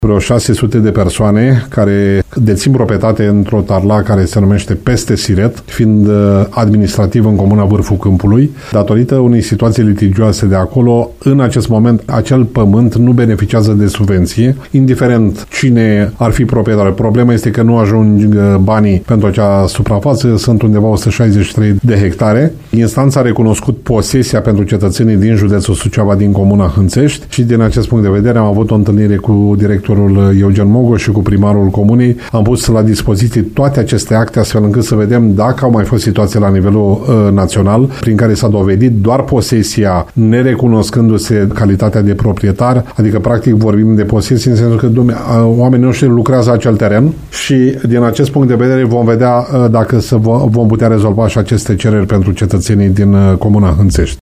El a declarat postului nostru că, în județ, sunt înregistrate peste 15 mii cereri de subvenții plătite din fonduri europene, în general pentru parcele cu suprafețe mici.